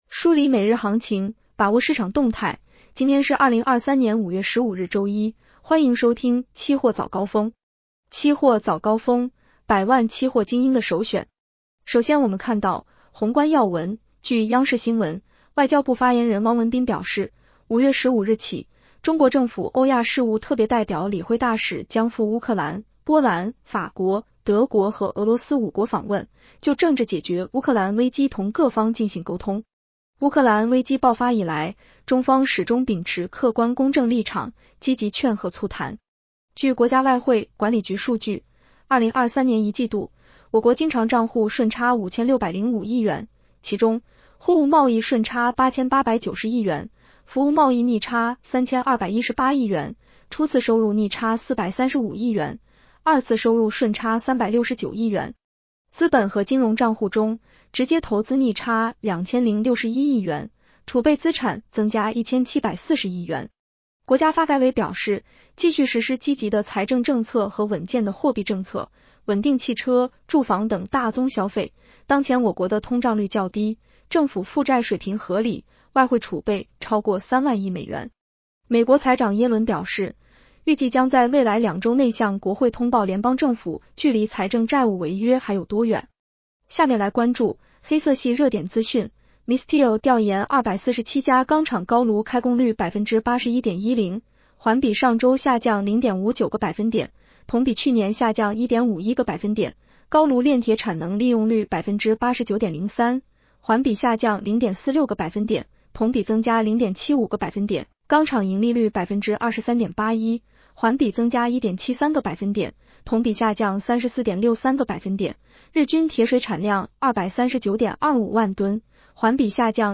【期货早高峰-音频版】 女声普通话版 下载mp3 宏观要闻 1.